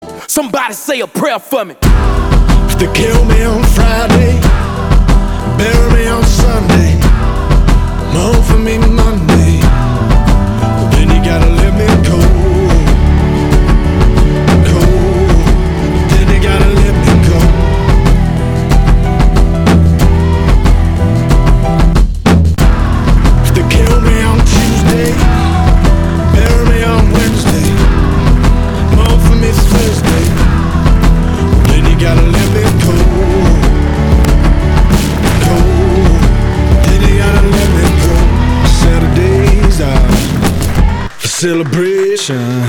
• Качество: 320, Stereo
Хип-хоп
Rap-rock
alternative
Альтернативный хип-хоп для настроения и выделения из толпы.